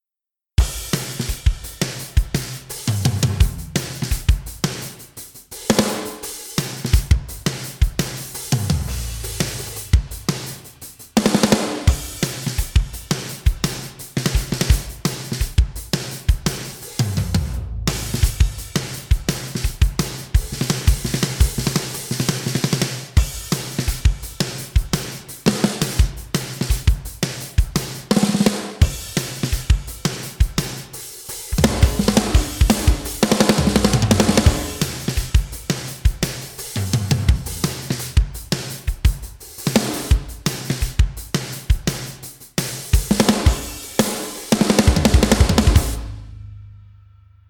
架子鼓 MixWave Underoath Aaron Gillespie KONTAKT-音频fun
MixWave: Underoath - Aaron Gillespie 提供了两种不同的鼓组合，一种是原声的，另一种是经过老式混音的，每种都包含了 2 个踢鼓、6 个军鼓、6 个鼓皮和 4 个铜钹。